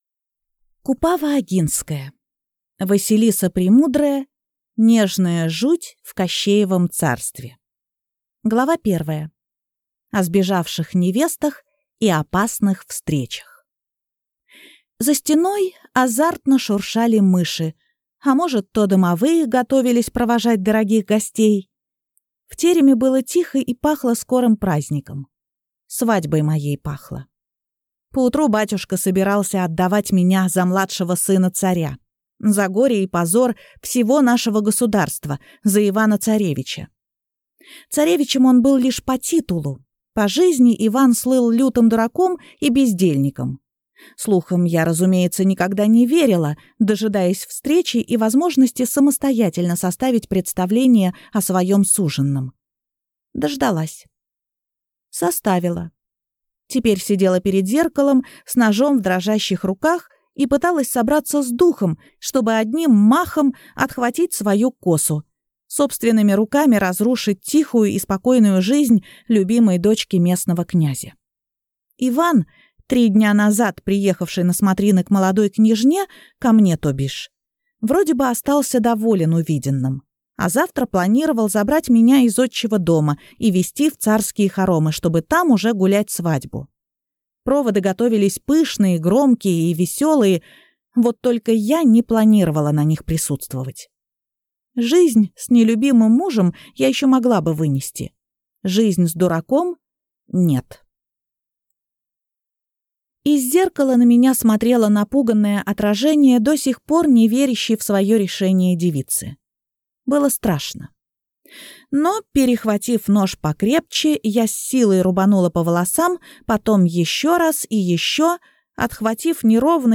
Аудиокнига Василиса Премудрая. Нежная жуть в Кощеевом царстве | Библиотека аудиокниг